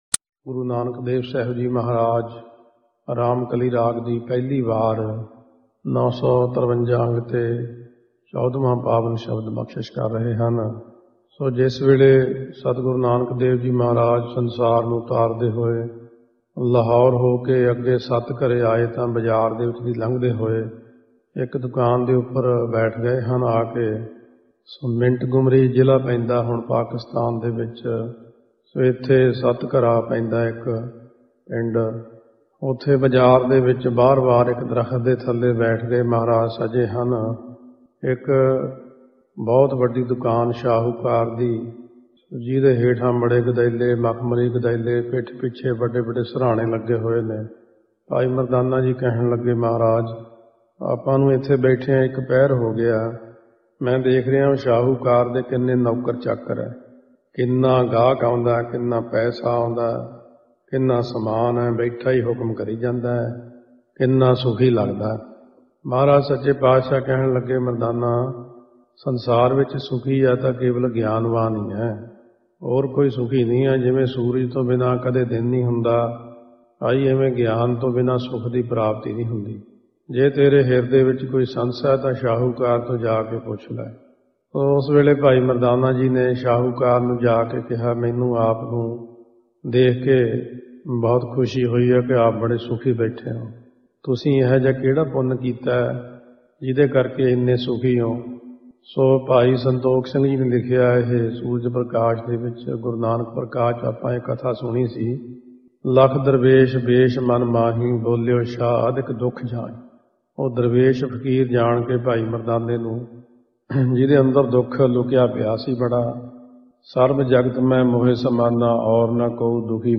Katha on DUKH.mp3